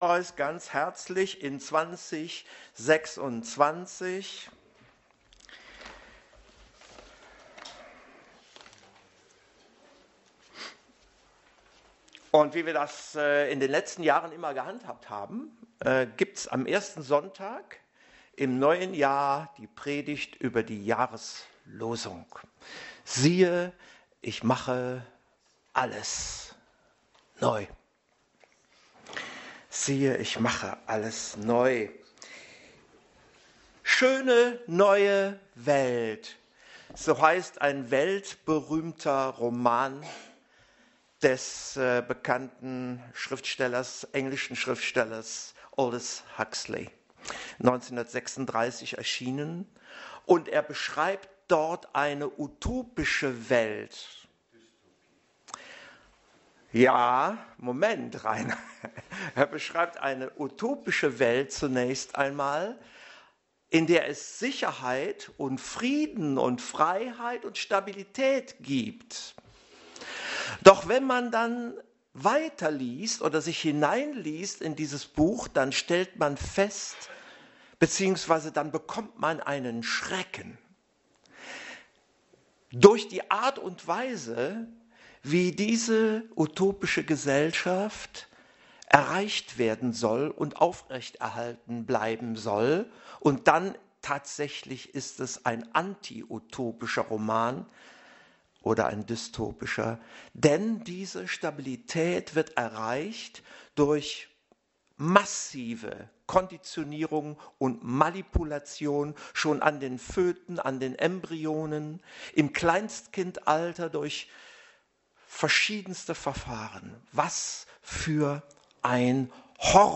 Dienstart: Predigt